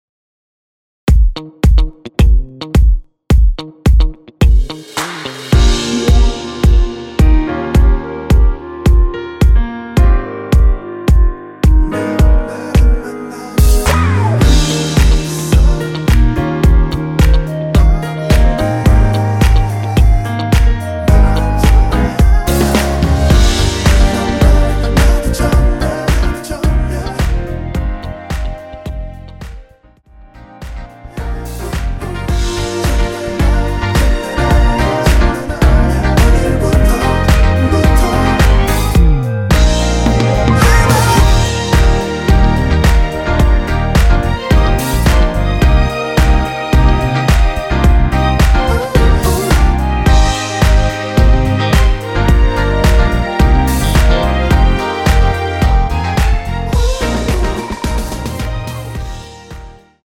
원키에서(-1)내린 멜로디와 코러스 포함된 MR입니다.(미리듣기 확인)
Eb
앞부분30초, 뒷부분30초씩 편집해서 올려 드리고 있습니다.
중간에 음이 끈어지고 다시 나오는 이유는